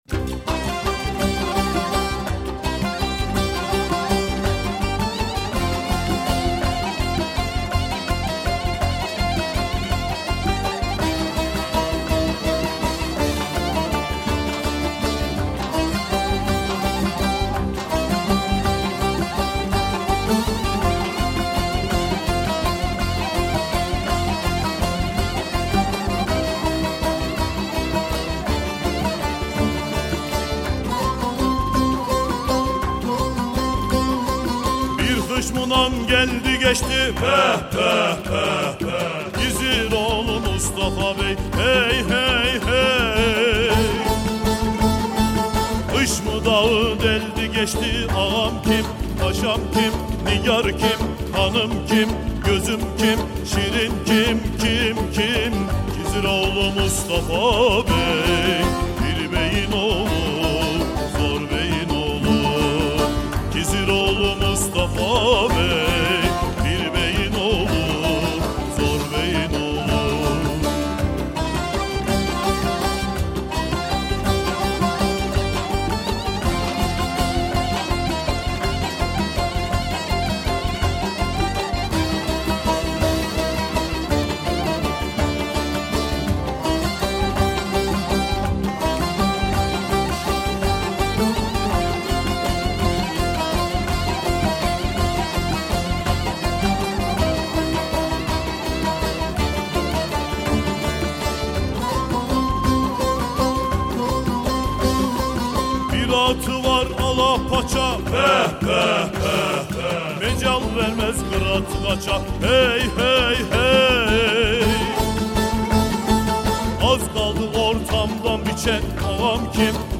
Etiketler: türkiye, türkü, kars türkü